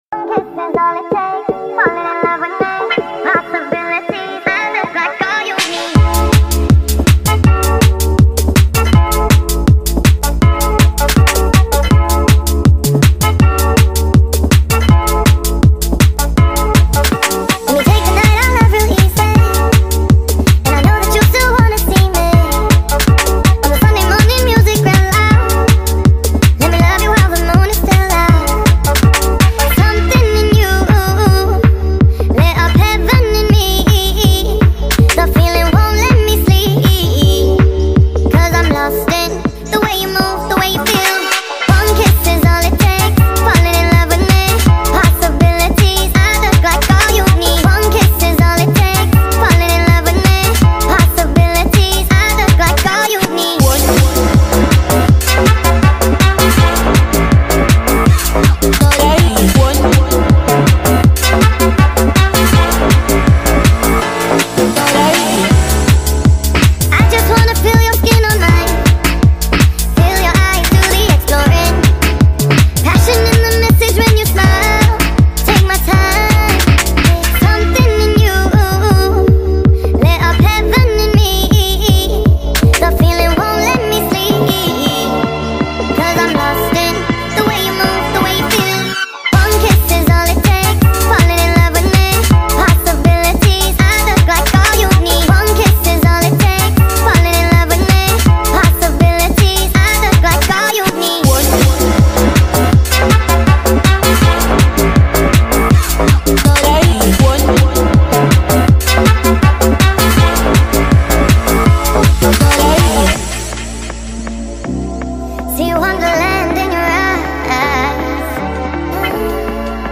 با صدای بچه (نسخه افزایش سرعت)